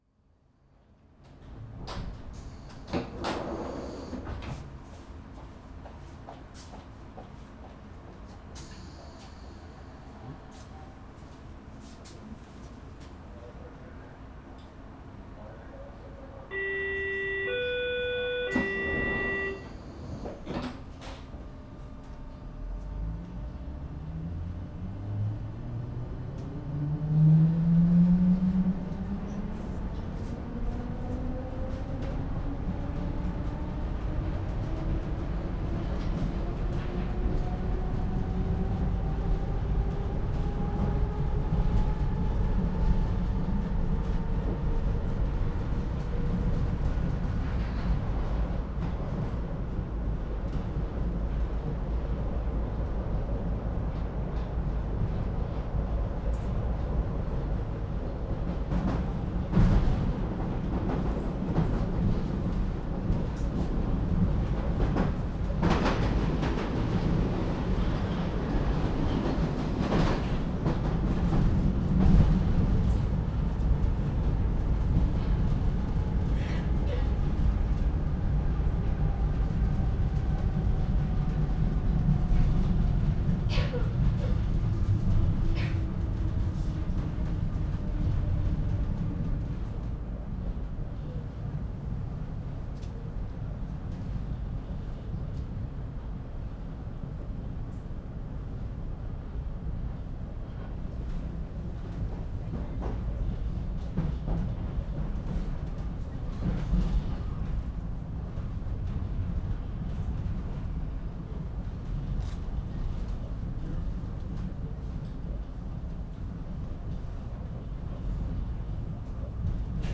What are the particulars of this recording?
S-Bahn City Train from inside(new one from East Berlin) 2:58